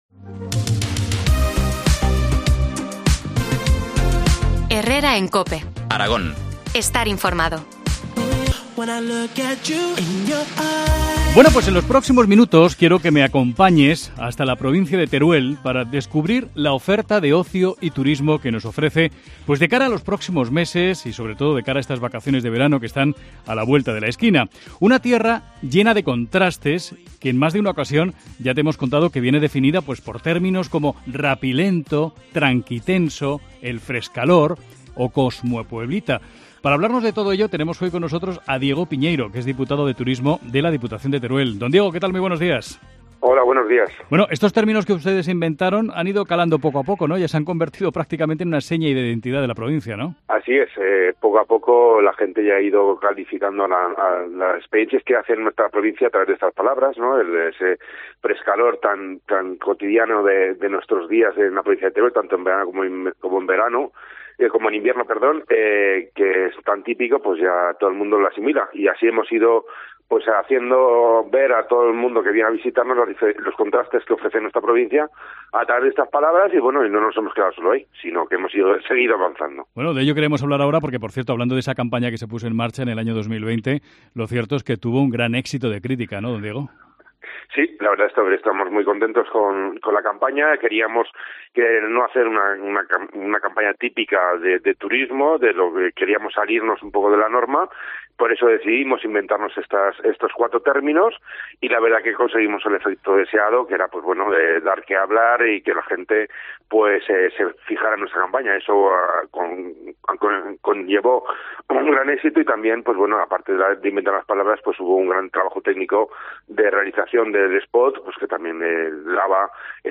Entrevista a Diego Piñeiro, diputado de Turismo de la Diputación de Teruel.